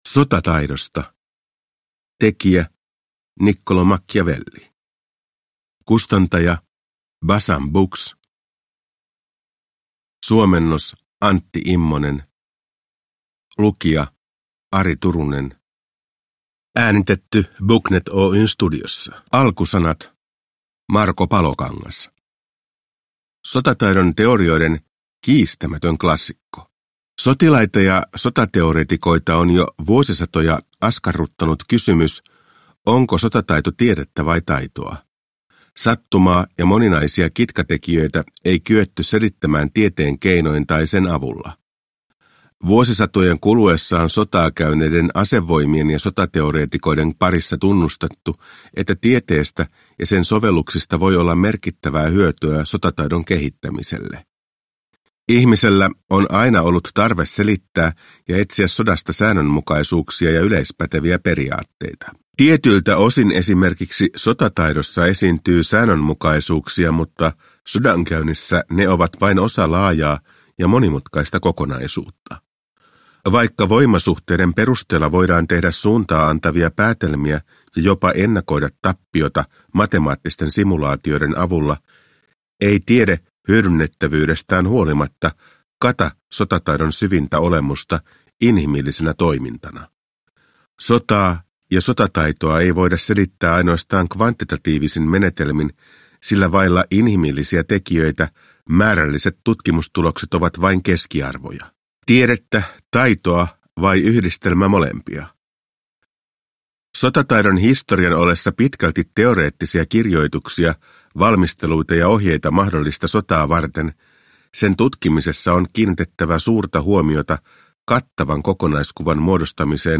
Sotataidosta – Ljudbok